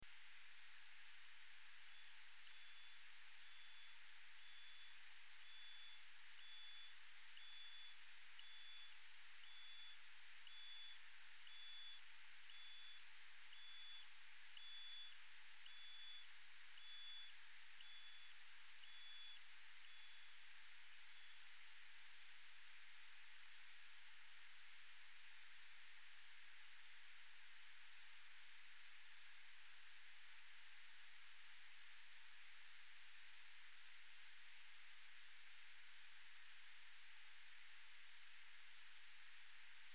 Eastern Horseshoe Bat – Full spectrum bat calls, NSW, Australia
Eastern Horseshoe Bat
Characteristic frequency 66 to 70 kHz. Perfectly flat, except for up sweeping initial section, and down sweeping tail. Sometimes displaying identical shaped pulses at other harmonics.
A typical sequence:
Two individuals flying together. Note how the echo clouds in the beginning are positioned a bit up in pitch, indicating that the bats are flying away from the microphone.